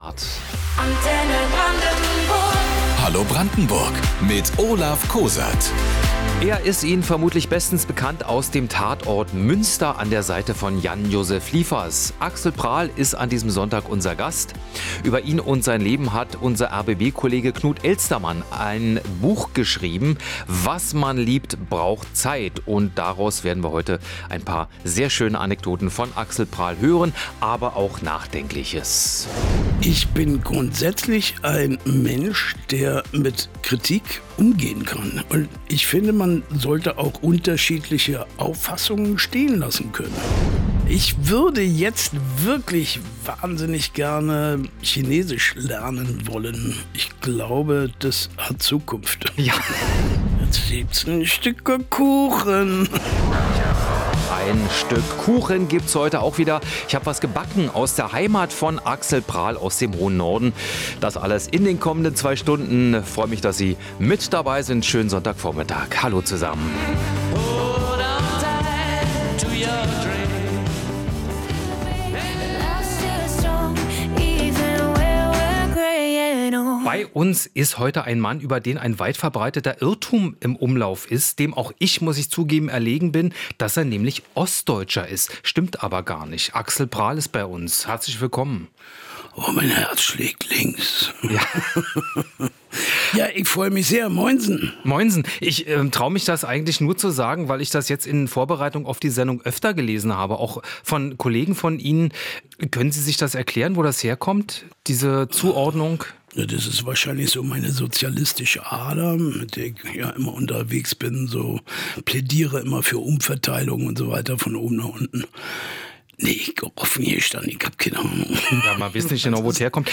Vor seiner Karriere als Schauspieler hat er als Kellner, Bierfahrer und Gleisbauer gearbeitet: Axel Prahl war am Sonntag unser Gast.